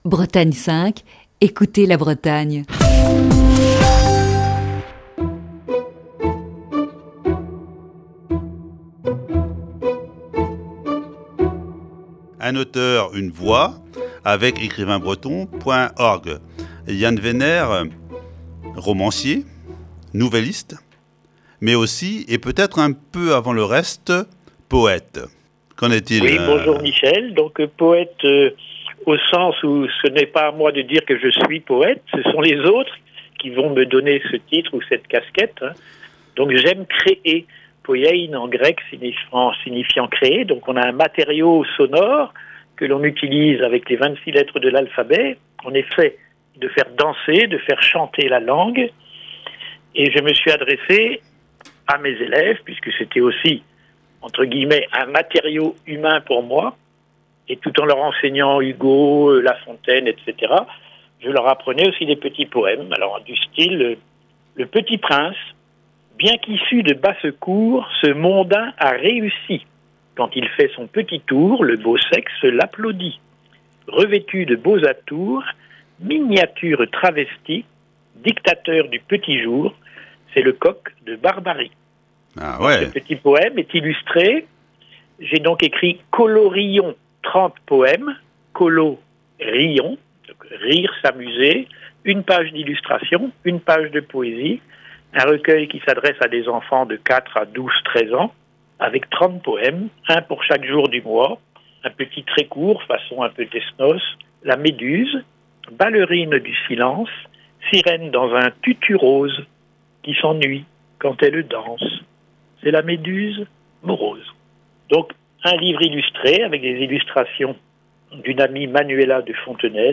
Ce jeudi, quatrième partie de cet entretien.